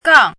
chinese-voice - 汉字语音库
gang4.mp3